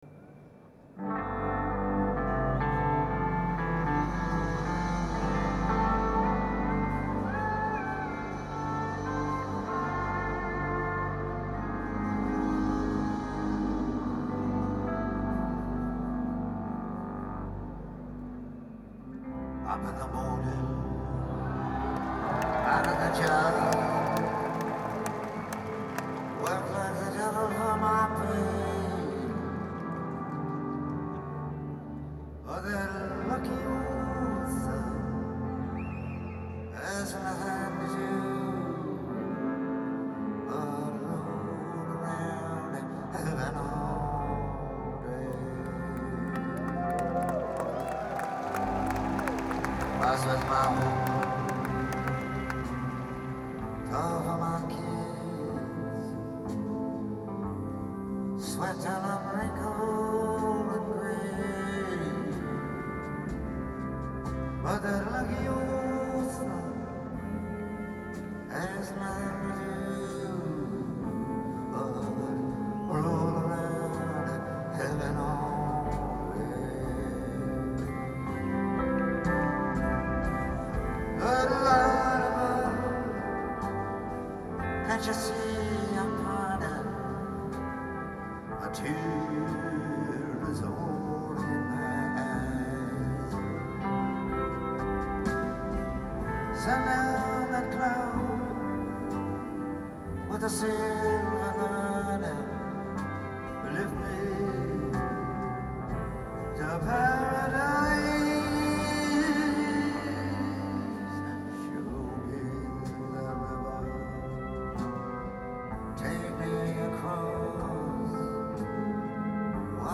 Donostia Arena 2016 (Illunbe) - San Sebastián, Spain